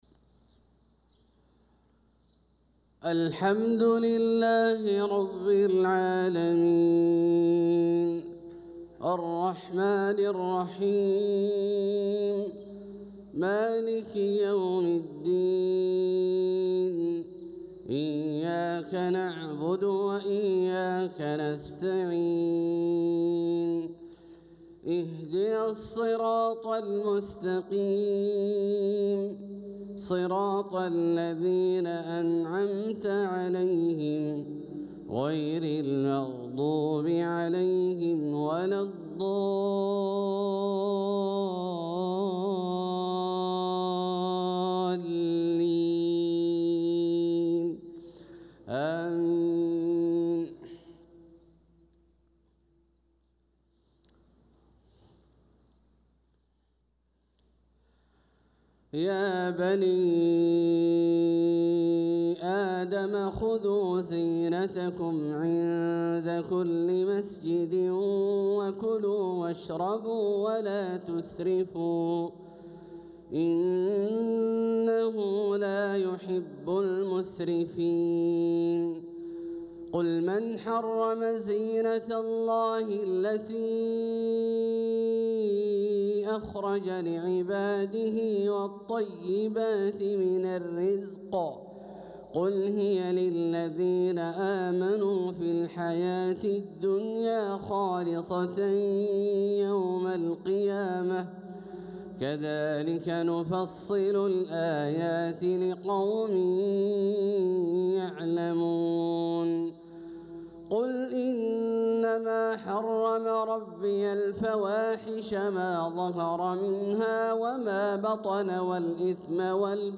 صلاة الفجر للقارئ عبدالله الجهني 10 صفر 1446 هـ
تِلَاوَات الْحَرَمَيْن .